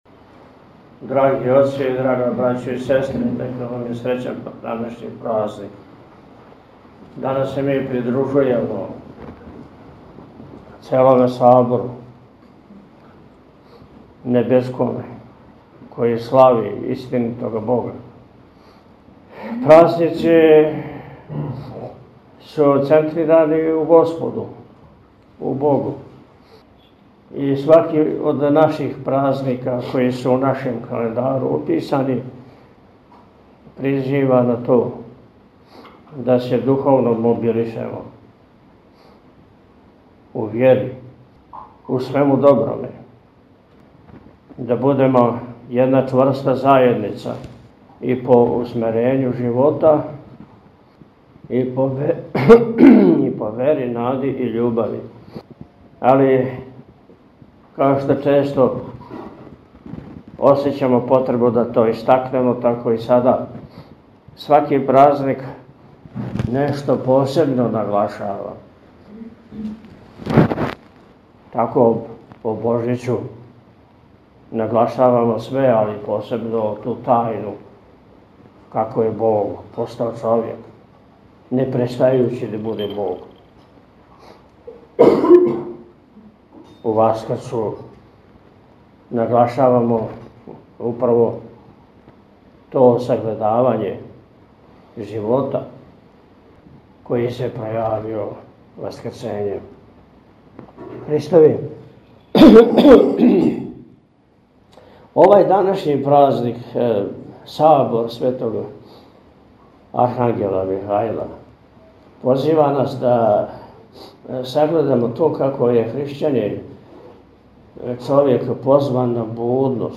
Празник Сабор Светог Архангела Михаила и осталих Небеских Сила Бестелесних – Аранђеловдан молитвено и свечано је прослављен у манастиру Милешеви у четвртак 21. новембра 2024. [...]
Тим поводом Његово Високопреосвештенство Архиепископ и Митрополит милешевски г. Атанасије служио је Свету архијерејску Литургију у параклису Свете Касијане.